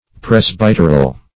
Meaning of presbyteral. presbyteral synonyms, pronunciation, spelling and more from Free Dictionary.